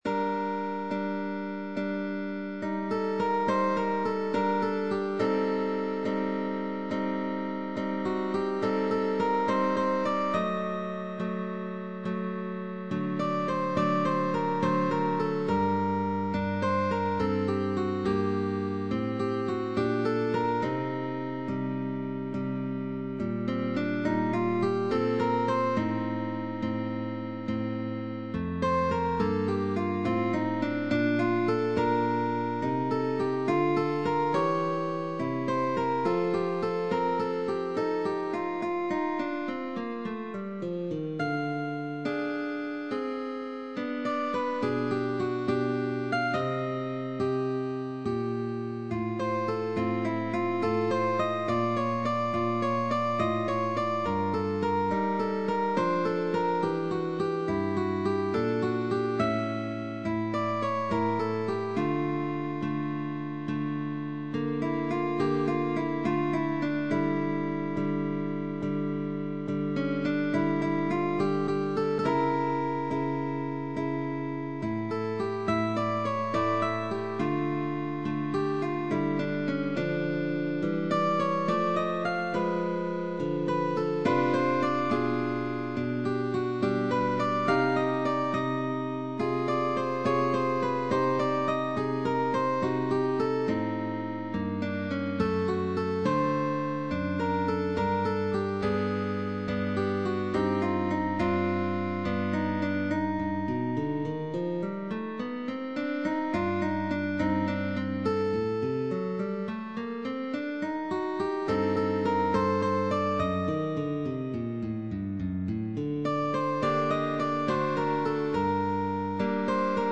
GUITAR DUO "Aria"
Change of position, Slurs, Chords of two and three notes,...